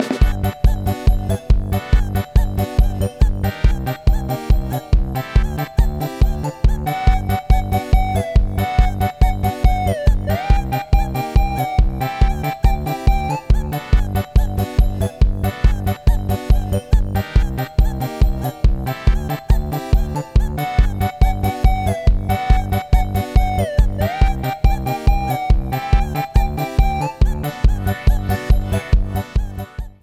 Multiplayer menu piece
Trimmed file to 30 seconds, applied fadeout